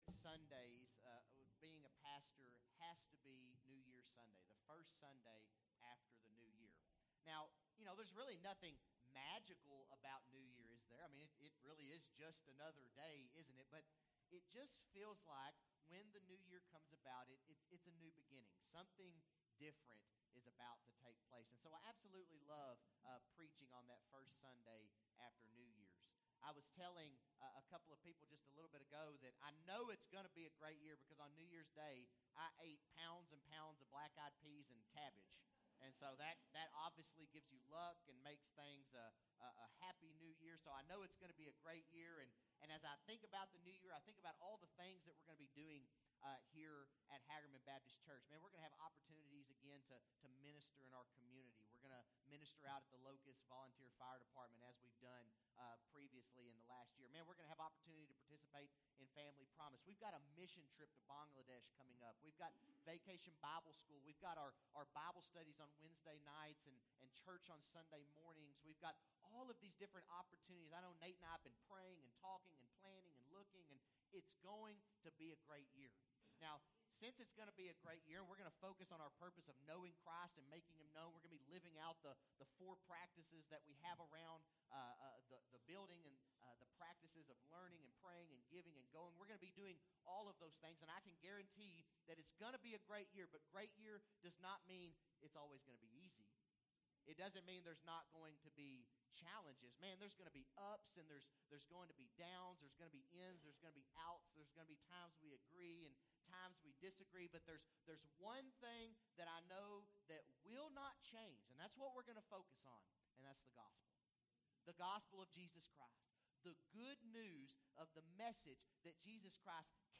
Bible Text: Galatians 1:1-5 | Preacher